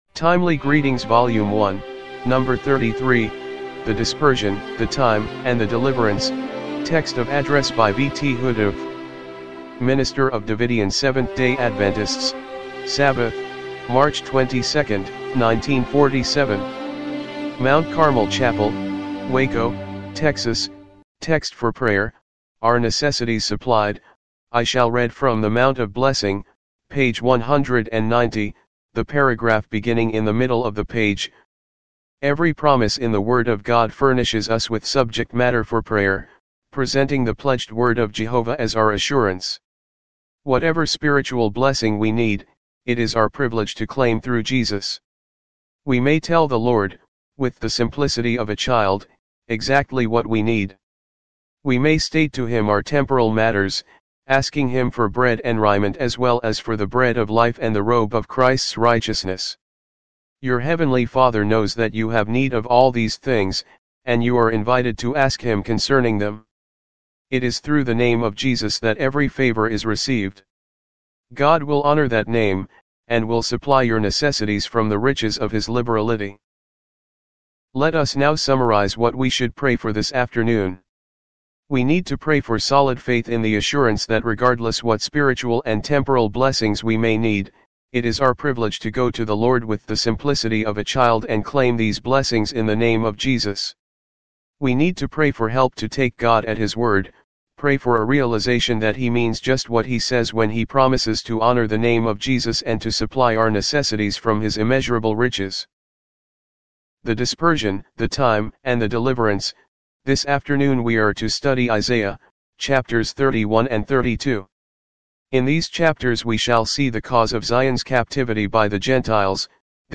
TEXT OF ADDRESS